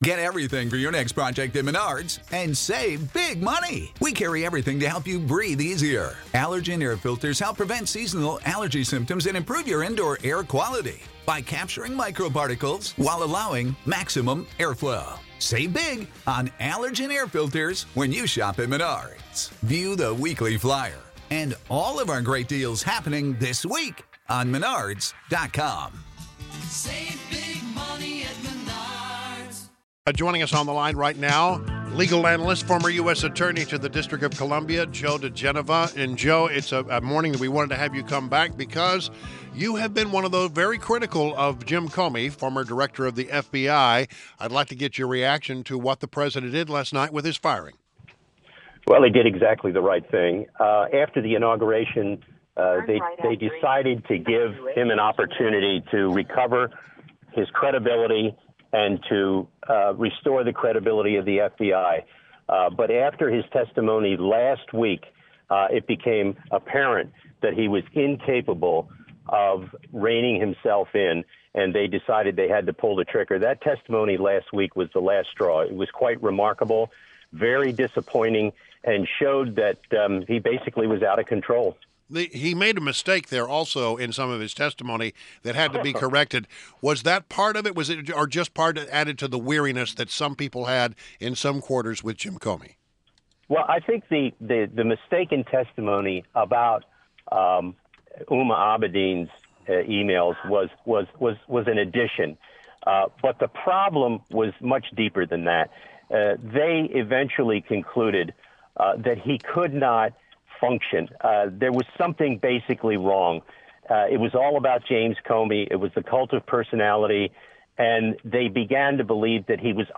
WMAL Interview - JOE DIGENOVA - 05-10-17